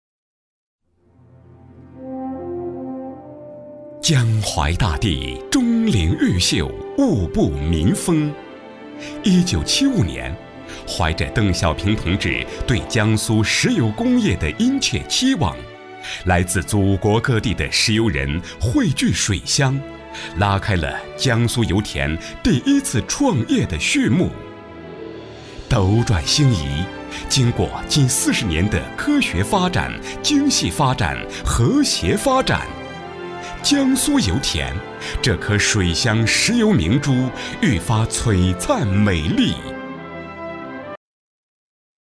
男62